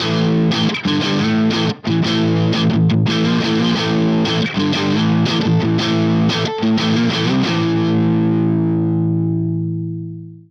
Marshall style Amp
gitmarshallstyleamp_1.wav